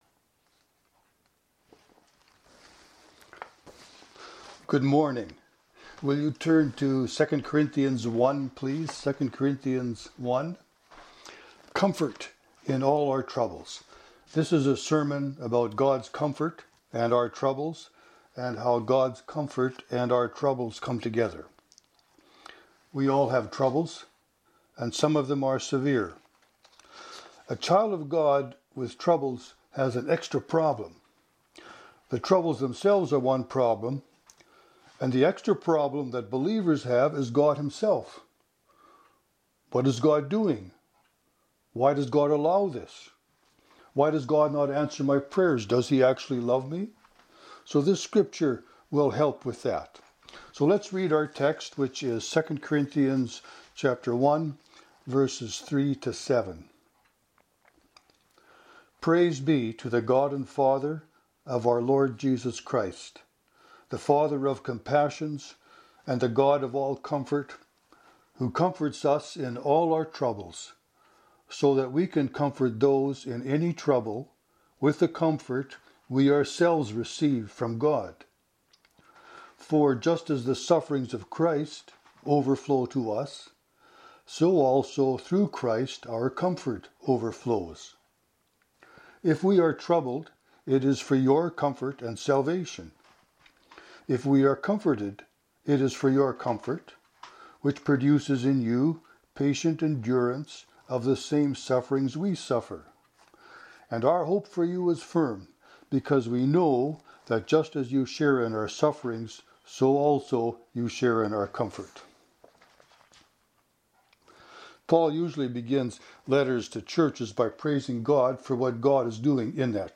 This is a sermon about God’s comfort, and our troubles, and how God’s comfort and our troubles come together.